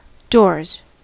Ääntäminen
Ääntäminen US Haettu sana löytyi näillä lähdekielillä: englanti Käännöksiä ei löytynyt valitulle kohdekielelle. Doors on sanan door monikko.